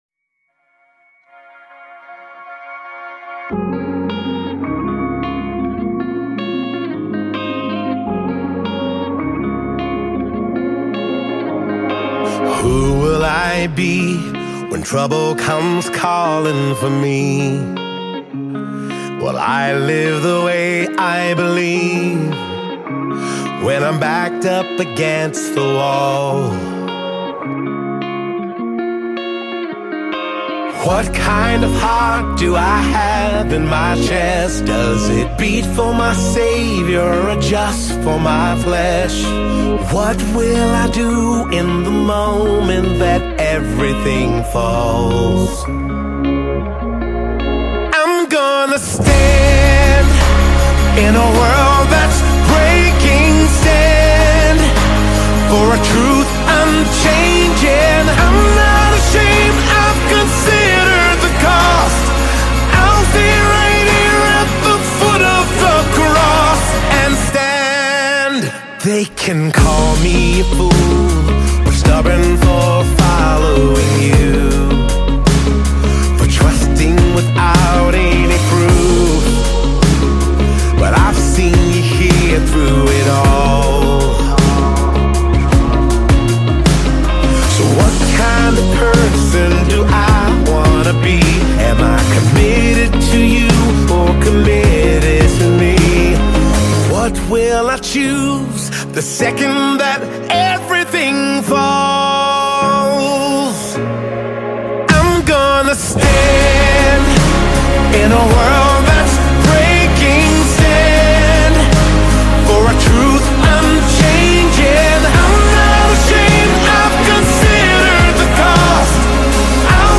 American gospel band
This soul lifting song is worth listening!